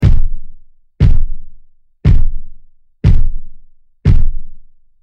Giant Stomping Footsteps 04
Giant_stomping_footsteps_04.mp3